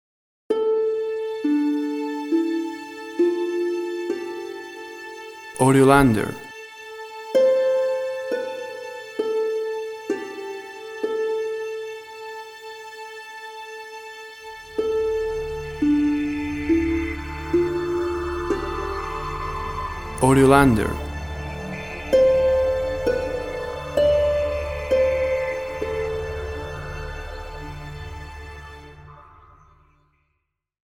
Tempo (BPM) 65